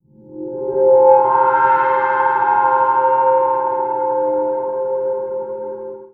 Index of /90_sSampleCDs/Trance_Formation/Atmospheric
30_Metallic_Ring_Mod.WAV